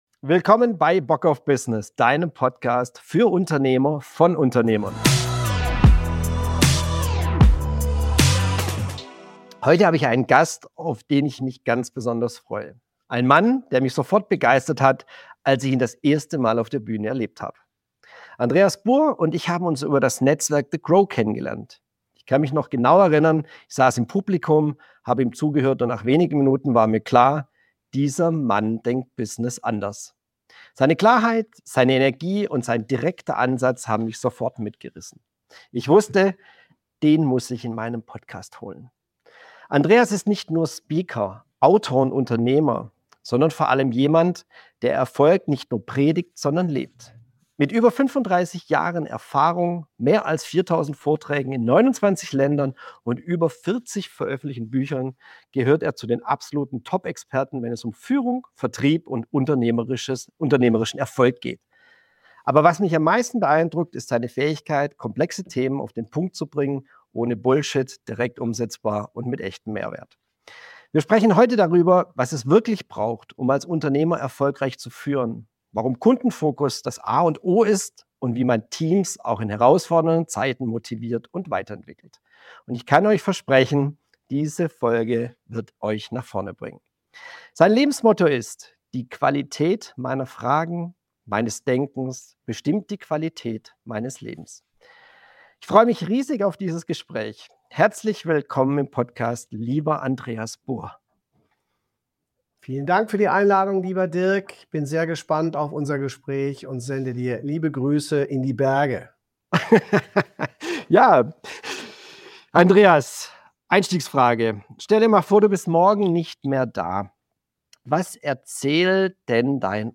Also habe ich ihn in meinen Podcast eingeladen. Wir sprechen darüber: Warum Führung und Vertrieb untrennbar zusammenhängen.